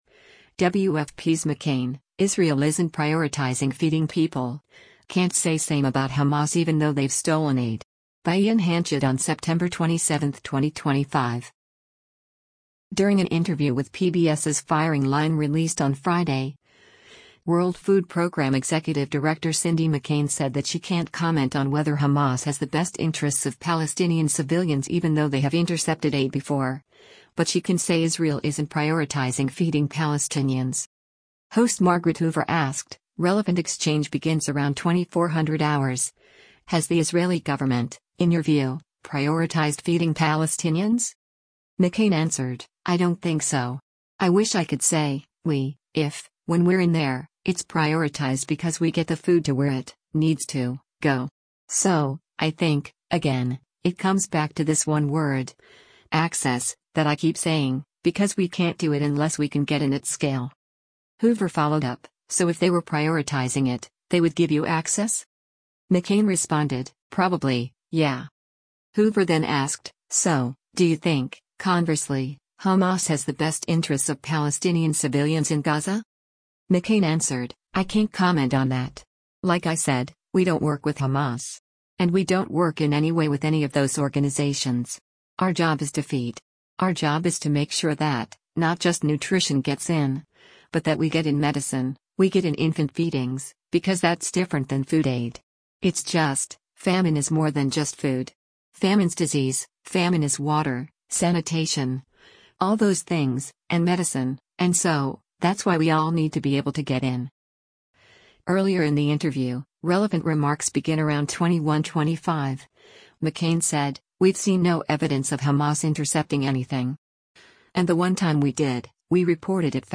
During an interview with PBS’s “Firing Line” released on Friday, World Food Programme Executive Director Cindy McCain said that she “can’t comment” on whether Hamas has the best interests of Palestinian civilians even though they have intercepted aid before, but she can say Israel isn’t prioritizing feeding Palestinians.